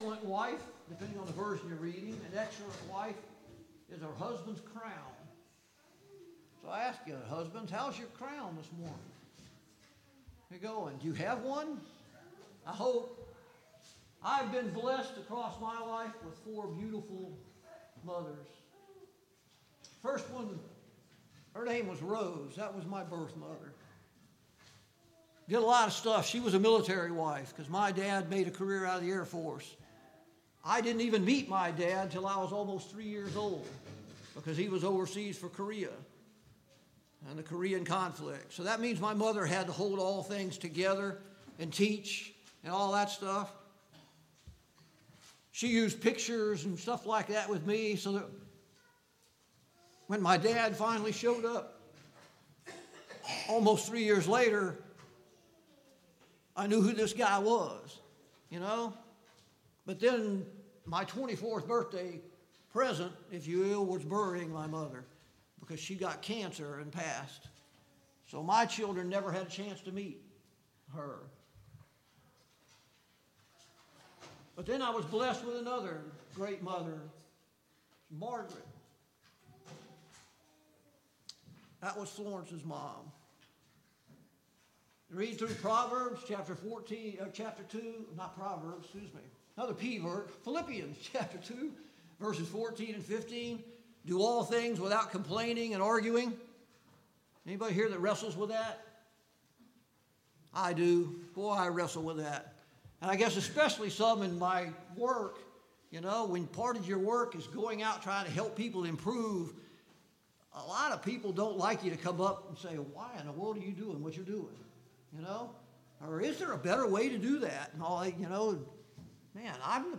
Passage: Proverbs 12:4 Service Type: AM Worship Bible Text